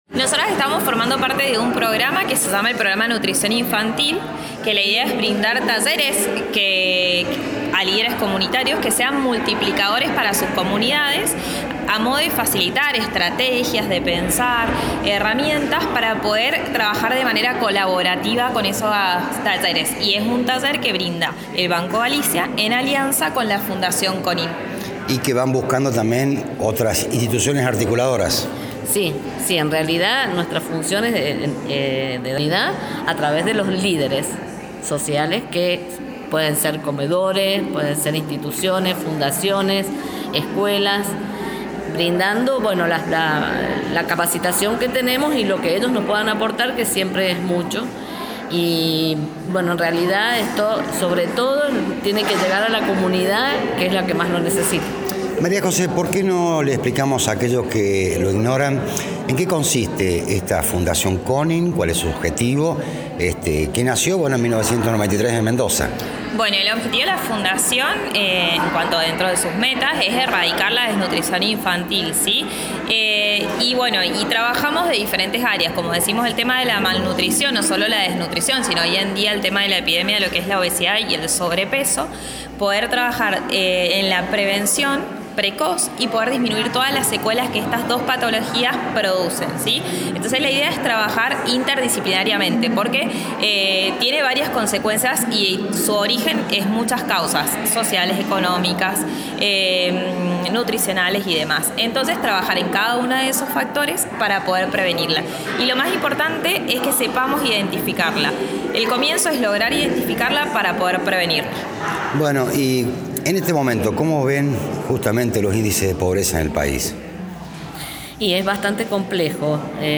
Radio UNViMe 87.9 dialogó con distintos protagonistas.
Responsables del taller